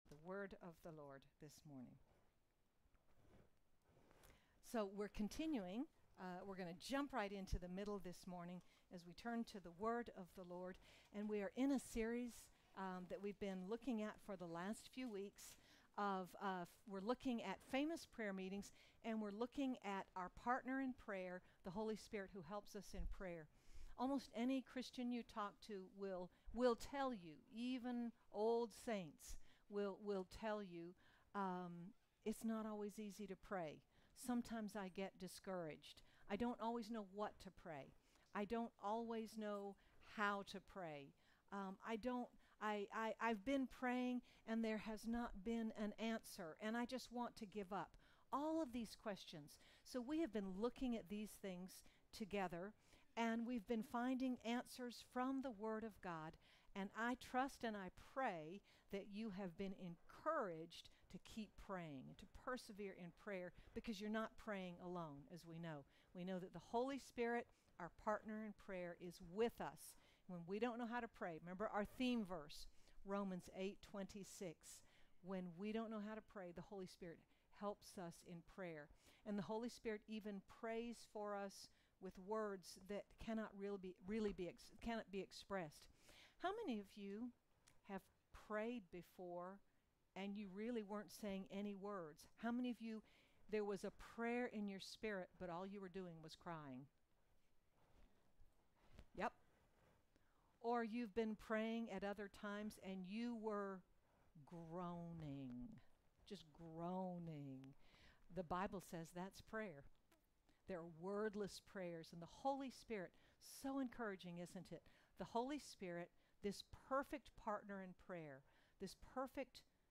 But God’s Word gives an encouraging example of His help when grow weak and weary in prayer. Sermon by